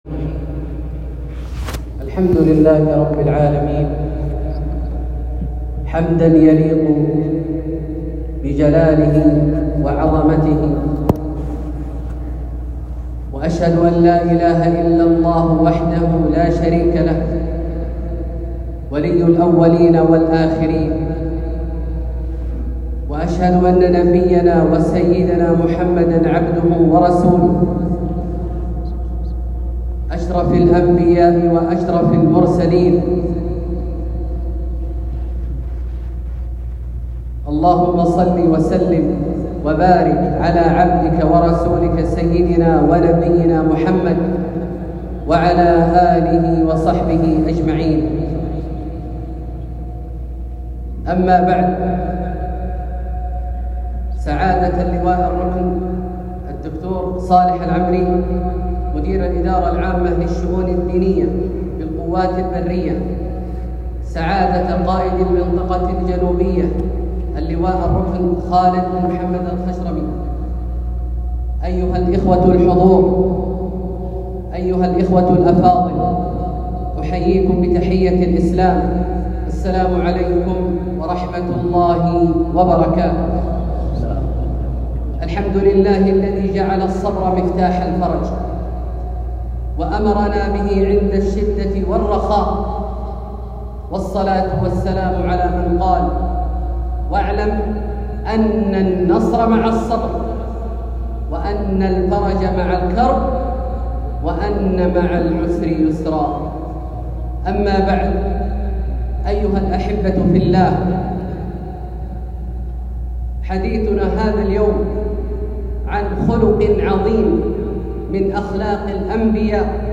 محاضرة للشيخ عبدالله الجهني في جامع الملك عبدالله بمدينة الملك فيصل العسكرية > زيارة فضيلة الشيخ أ.د. عبدالله الجهني للمنطقة الجنوبية | محرم 1447هـ > المزيد - تلاوات عبدالله الجهني